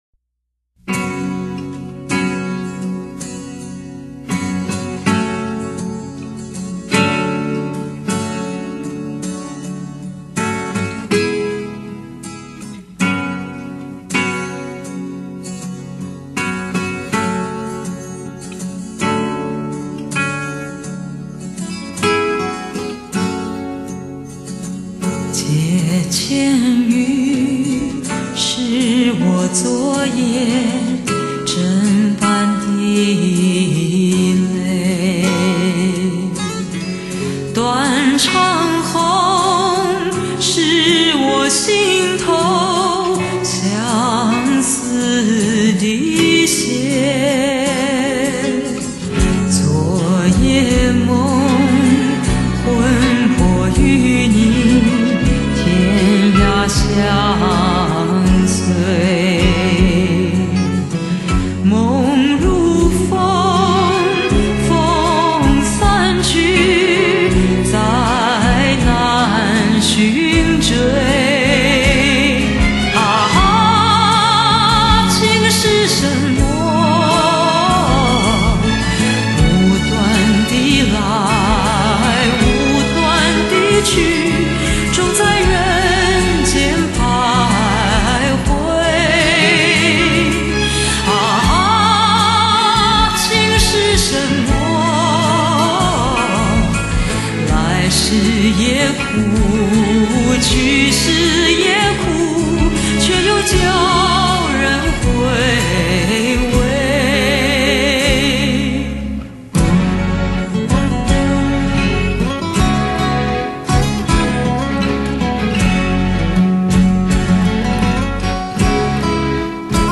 低沉优雅的嗓音，蕴含水着成性特有的性感。